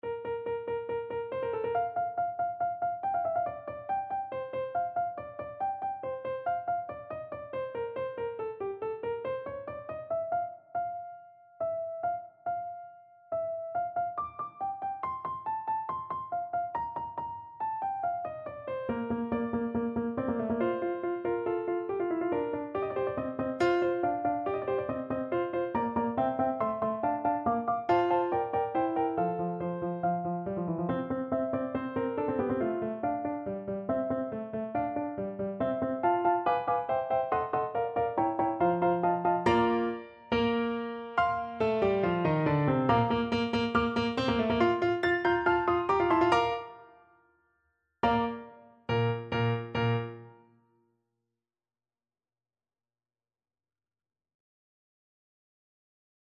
Play (or use space bar on your keyboard) Pause Music Playalong - Piano Accompaniment Playalong Band Accompaniment not yet available reset tempo print settings full screen
= 140 Allegro (View more music marked Allegro)
Bb major (Sounding Pitch) G major (Alto Saxophone in Eb) (View more Bb major Music for Saxophone )
Classical (View more Classical Saxophone Music)